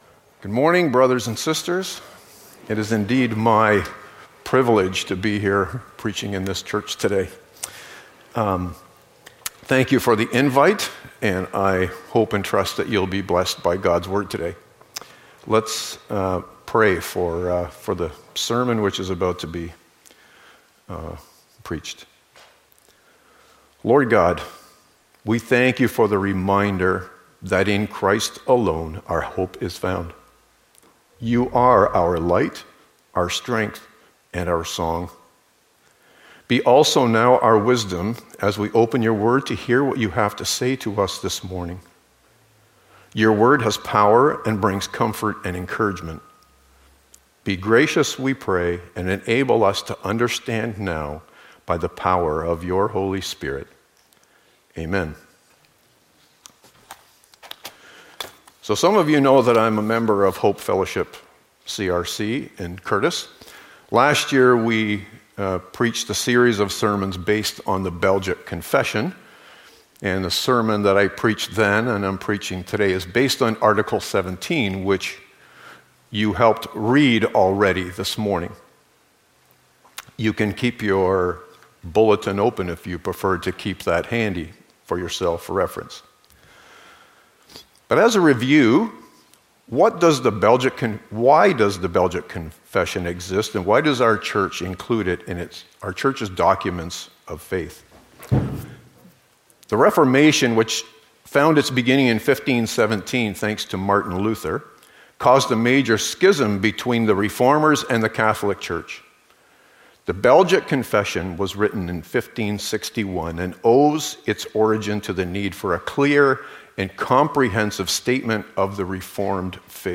Passage: Genesis 3:14-15; Galatians 4:4-7 Service Type: Sunday Morning